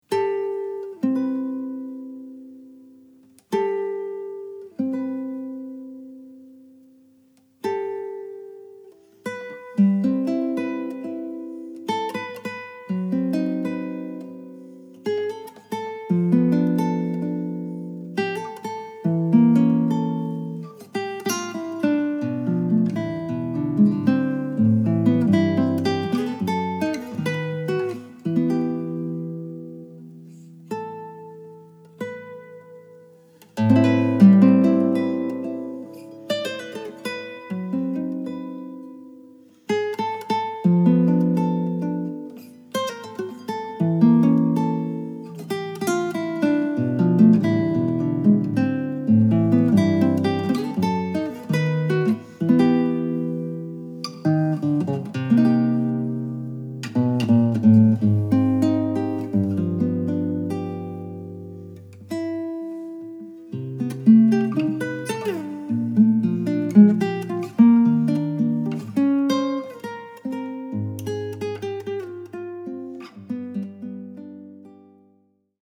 this album of solo guitar pieces is great for all occasions.
A variety of guitars were used during the performances.